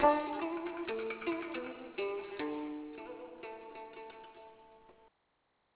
Moon Lute
Dan Nguyet (moon) or Dan Kim A 2-string with name refers to shape of the sound box. Unlike the Chinese yue'k'i`n, Dan Kim has long, slender fingerboard with unequal frets allows which extreme modulations.